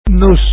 ME' CA 'S PRUNÜNCIA LA LENGUA 'D VARSEI